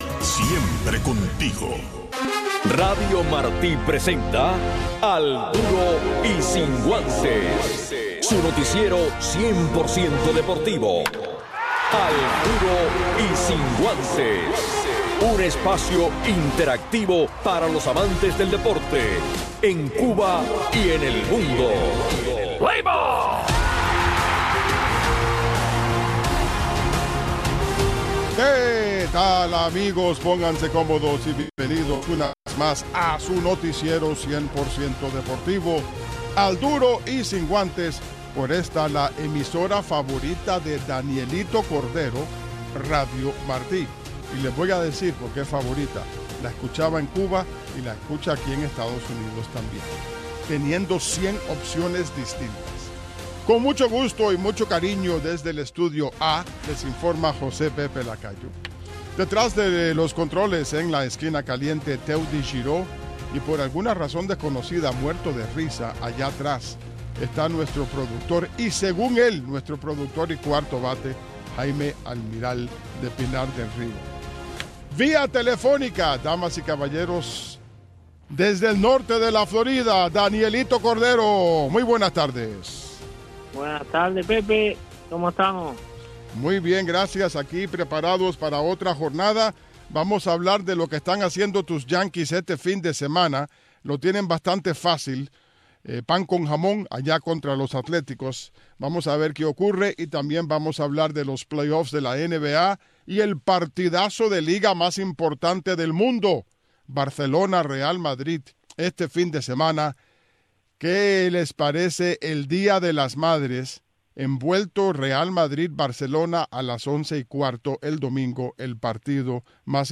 Un resumen deportivo en 60 minutos